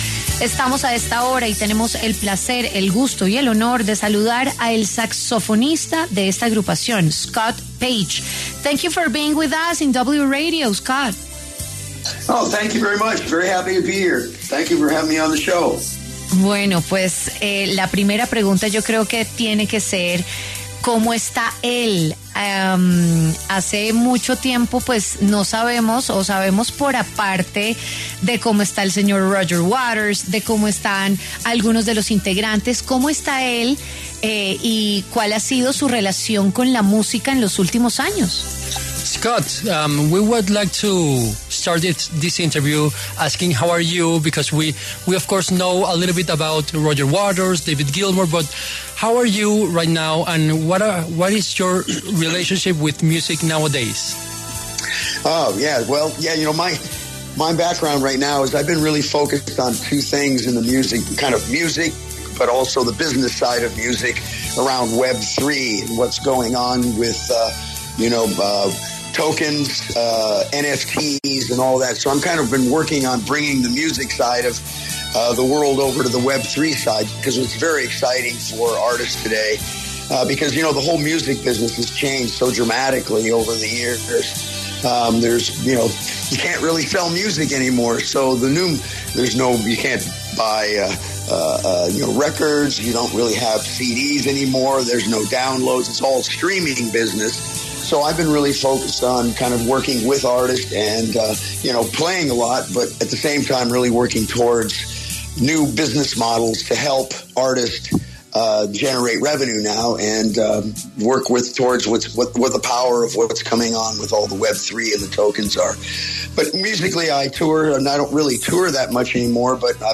Scott Page, el saxofonista de Pink Floyd que también triunfa en el mundo digital, habló en W Fin de Semana.